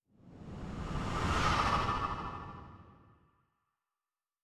Distant Ship Pass By 6_3.wav